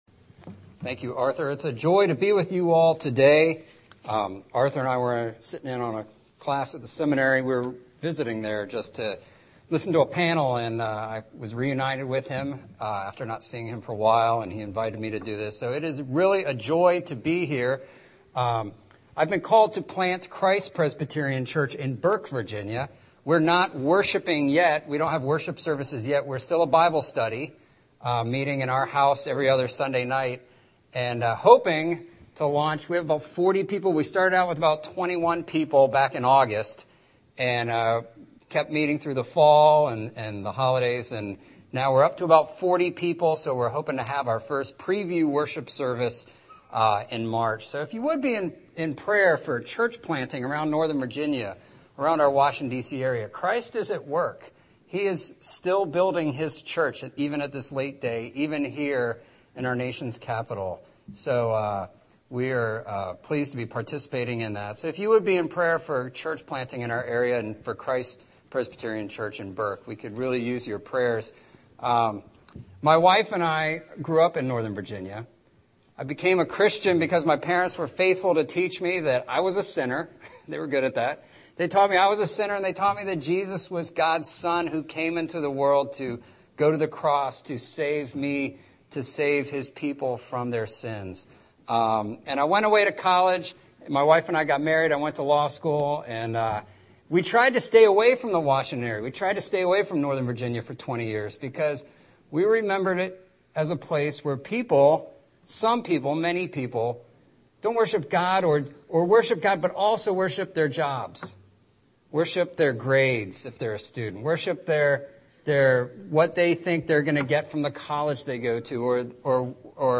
Scripture: Luke 10:38-42 Series: Sunday Worship